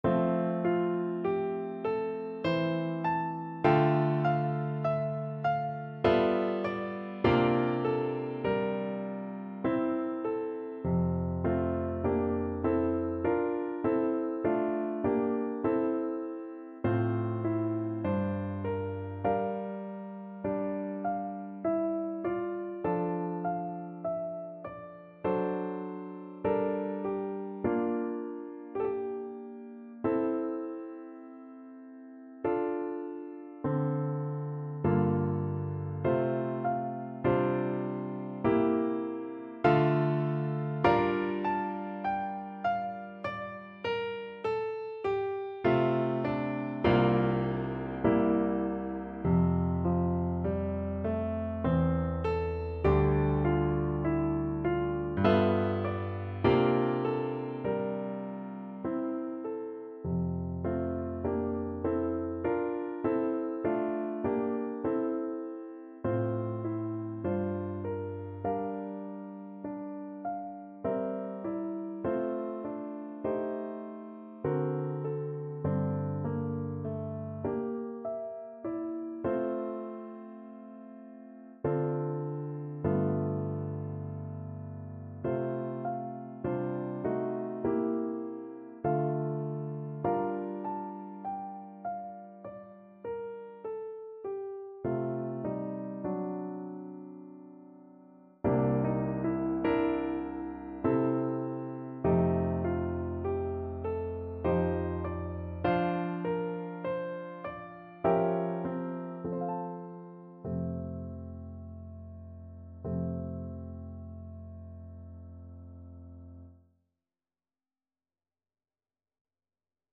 ~ = 50 Slow, with emphasis
3/4 (View more 3/4 Music)
Classical (View more Classical Voice Music)